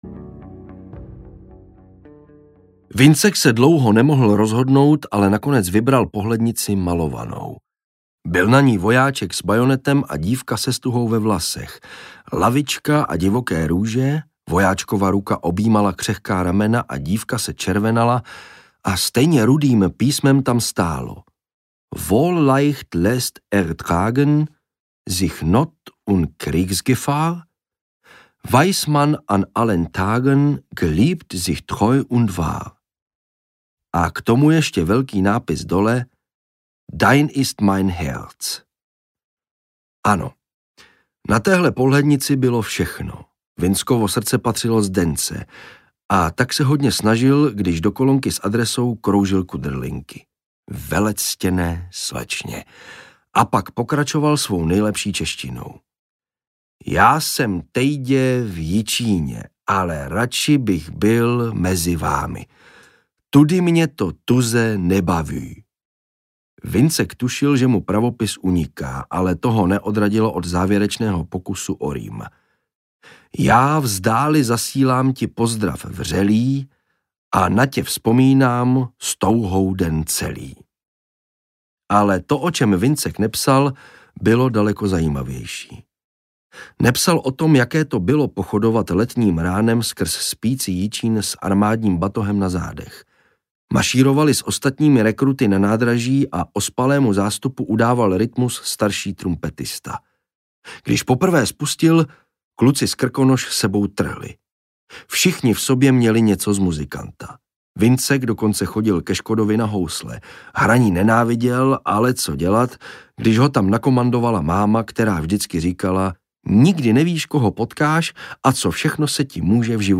Rozpůlený dům audiokniha
Audiokniha Rozpůlený dům, kterou napsala Alice Horáčková.
Ukázka z knihy